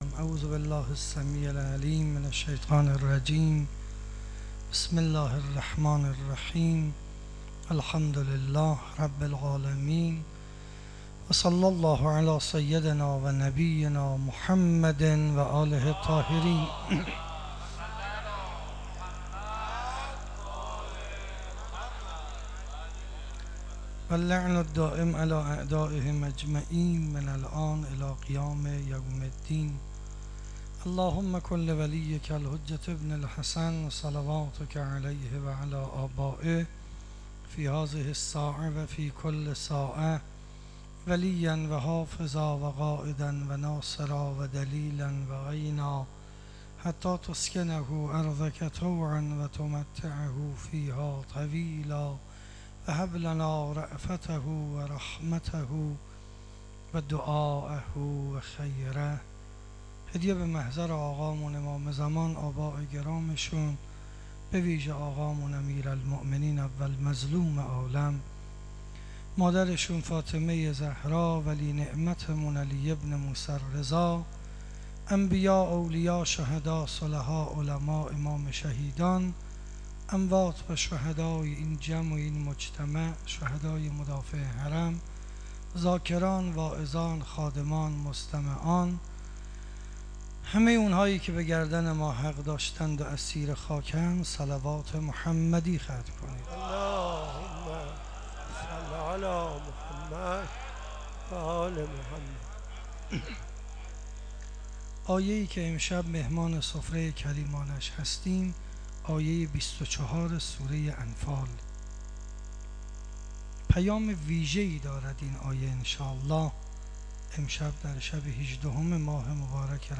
شب 18 رمضان 97- مسجد حضرت قاسم ابن الحسن علیهما السلام